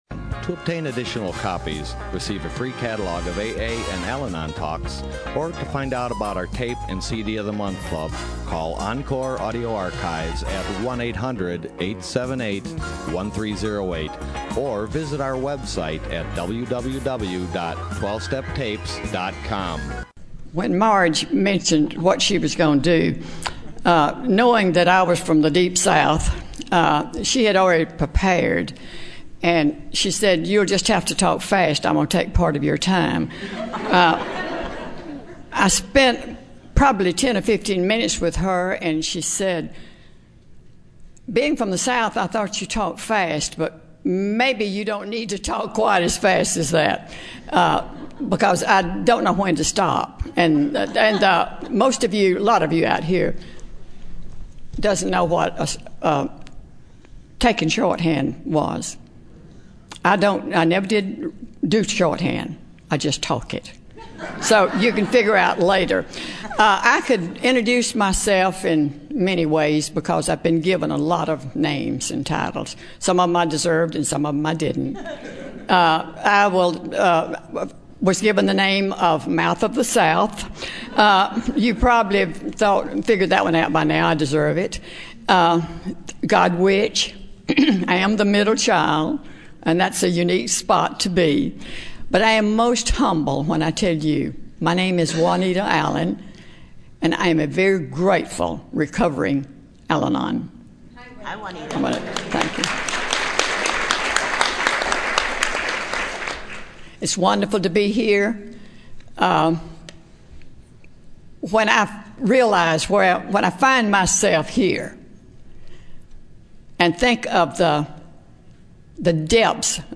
San Diego Spring Roundup 2007